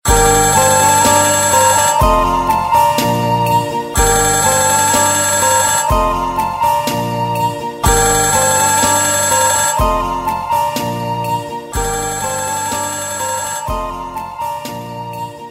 my-telephone_25421.mp3